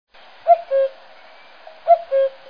Cuco
Canto del cuco
cantoCuco.mp3